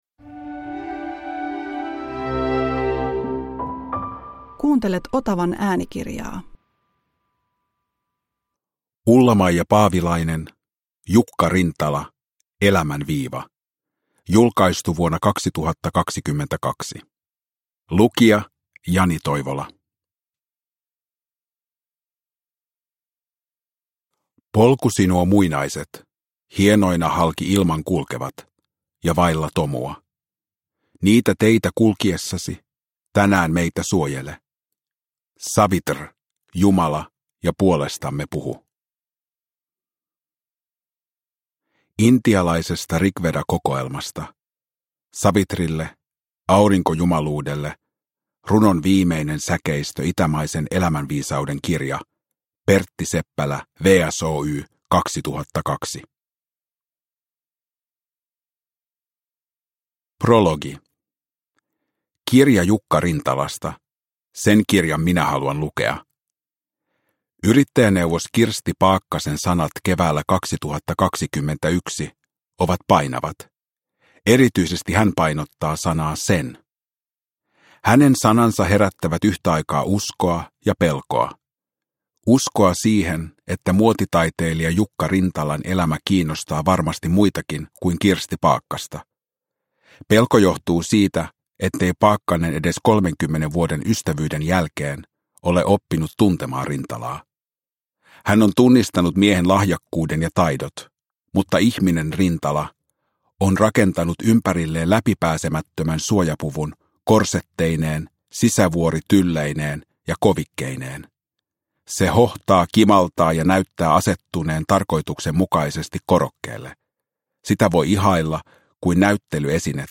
Jukka Rintala – Ljudbok – Laddas ner
Uppläsare: Jani Toivola